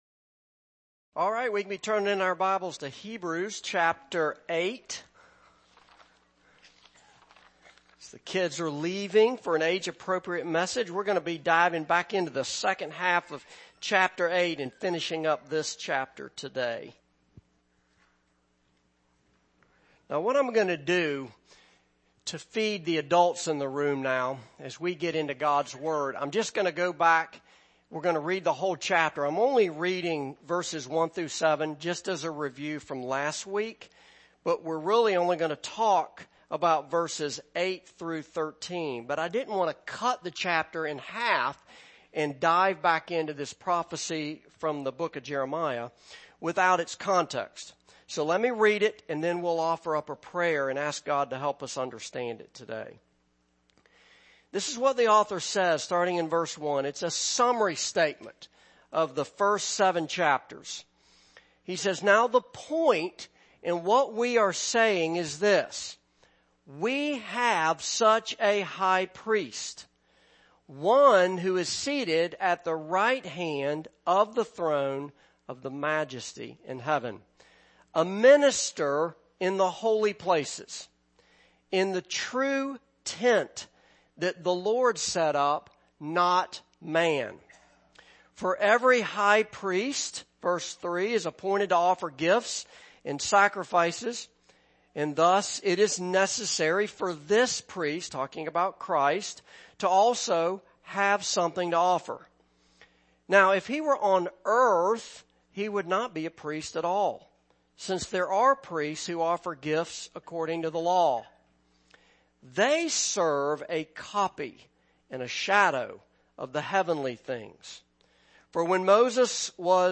Series: Hebrews Passage: Hebrews 8:8-13 Service Type: Morning Service